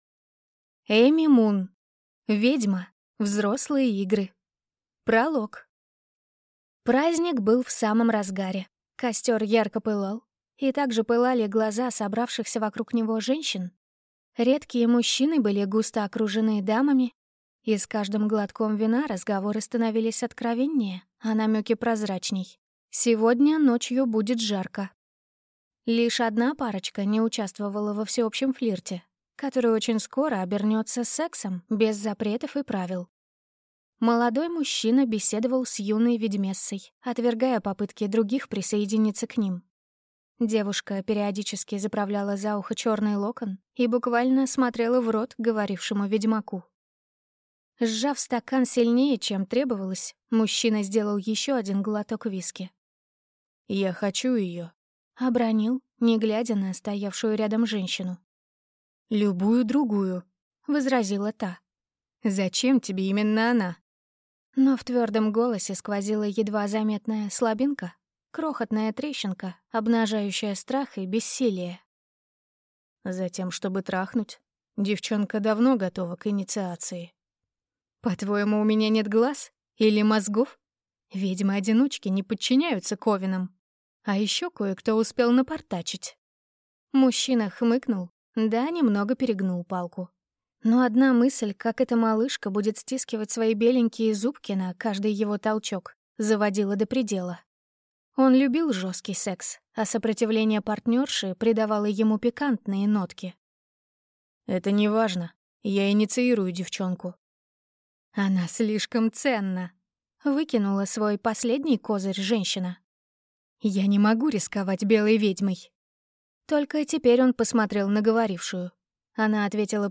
Аудиокнига Ведьма. Взрослые игры | Библиотека аудиокниг
Прослушать и бесплатно скачать фрагмент аудиокниги